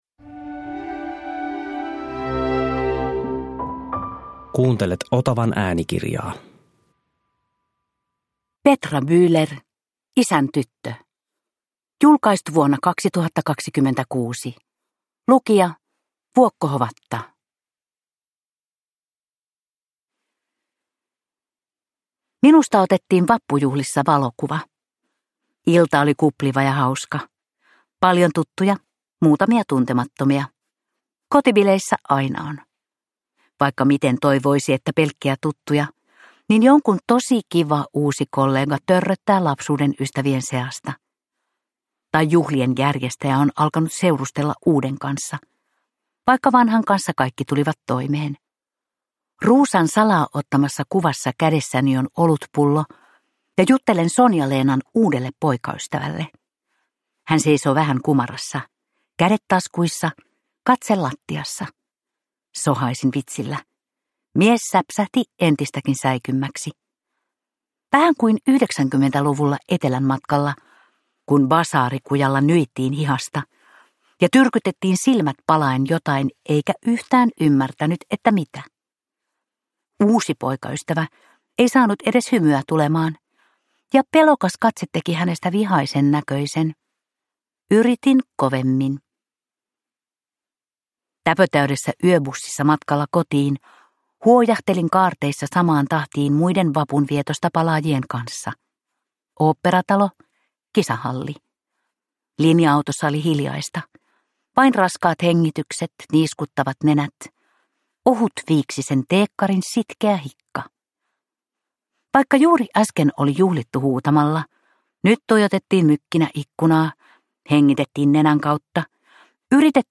Isän tyttö – Ljudbok